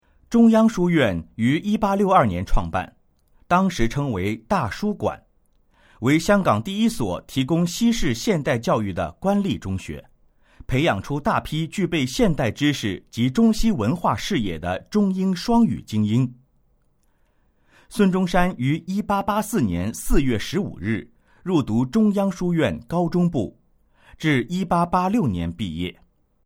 语音简介